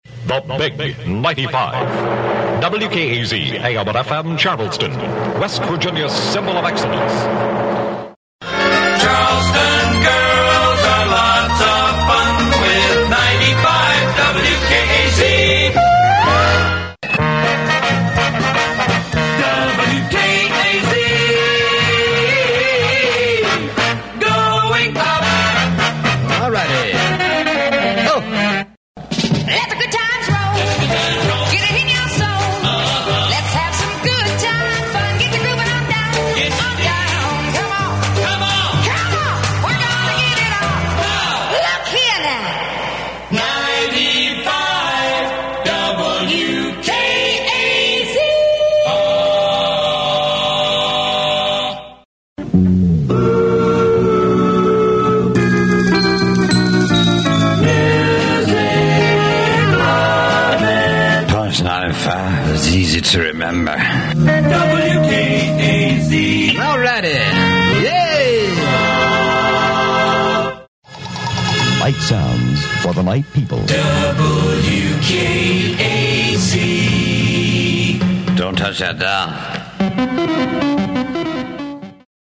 Station IDs
kazIDs.mp3